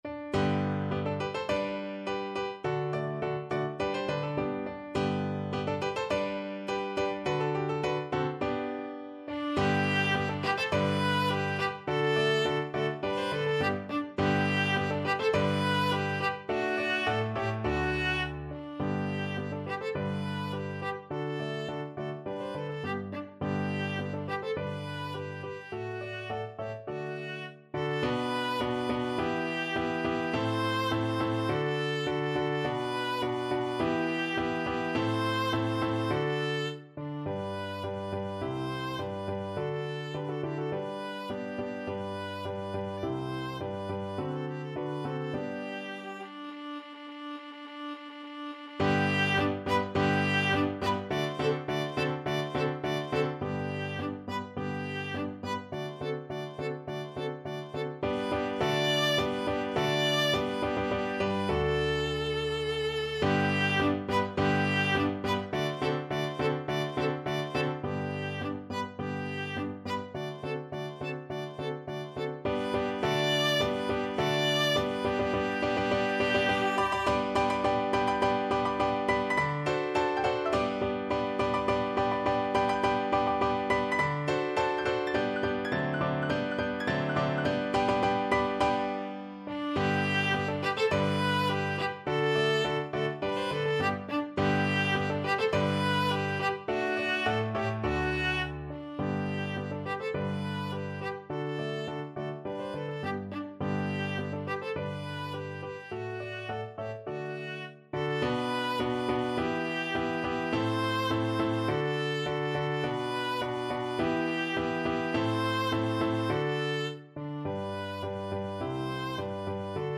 Free Sheet music for Viola
2/4 (View more 2/4 Music)
G major (Sounding Pitch) (View more G major Music for Viola )
~ = 100 Molto vivace =104
Viola  (View more Easy Viola Music)
Classical (View more Classical Viola Music)